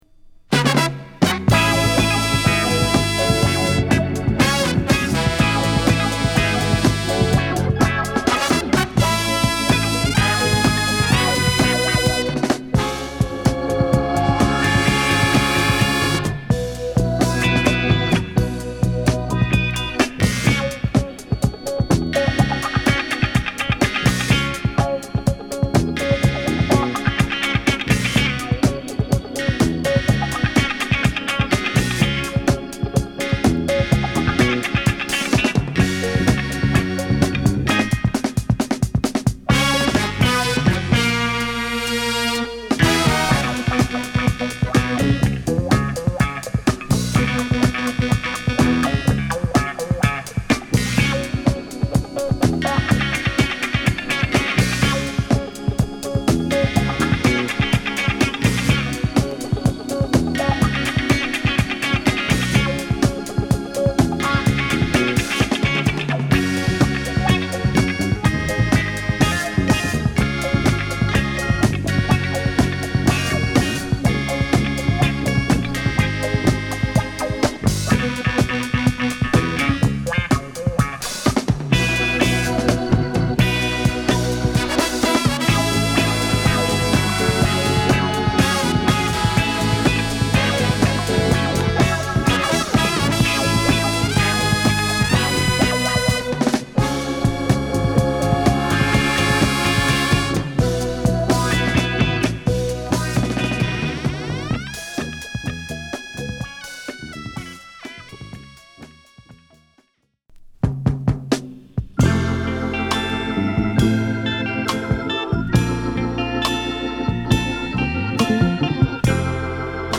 メロウから疾走ファンクまで収録。